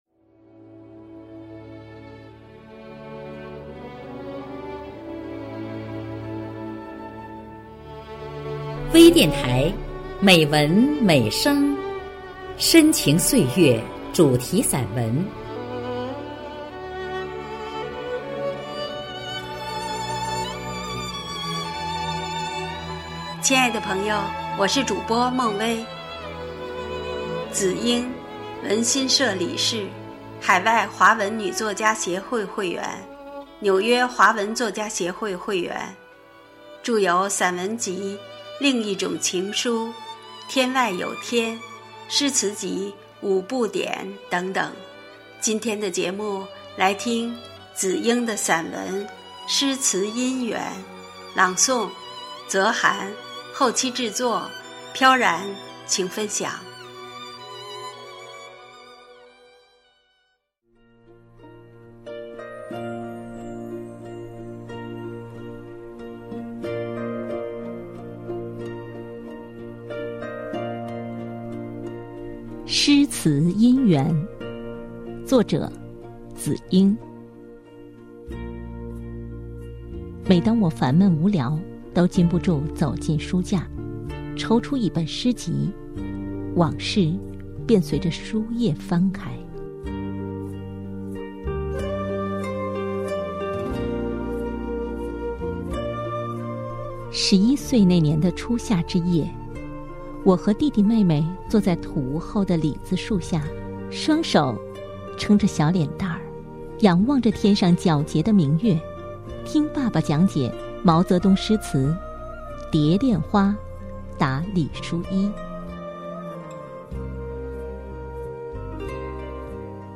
朗诵
专业诵读 精良制作